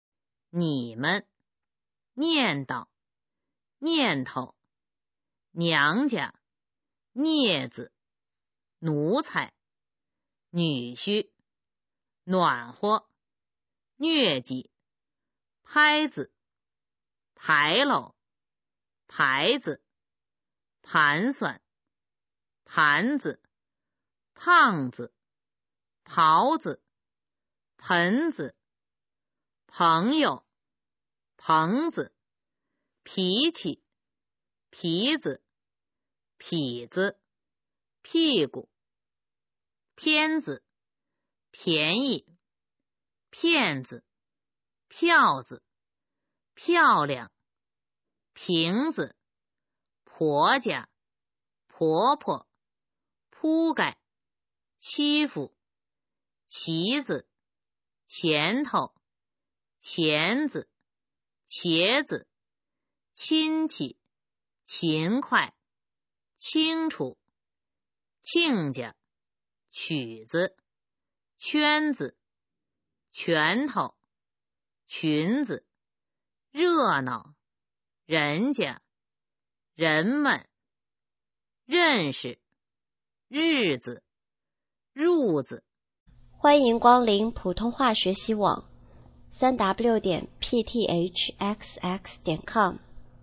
普通话水平测试用必读轻声词语表示范读音第301-350条